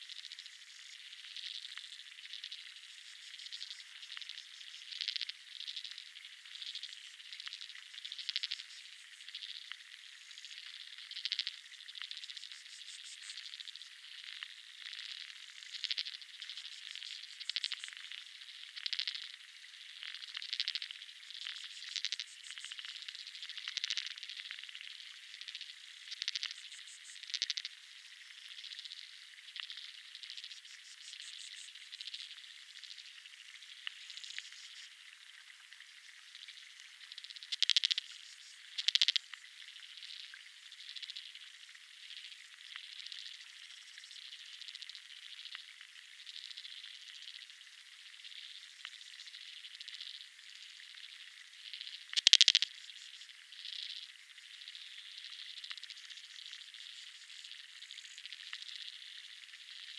It essentially involves lowering waterproof microphones into the water and recording what's picked up.
You'll hear a chorus of sharp clicks, like marbles rattling in a glass. The recording is filled with hundreds of near-identical calls - a task that would take hours to label by hand.
Waterbugs create a rhythmic chorus of sharp clicks.
waterbug-chorus-rivers-60sec.mp3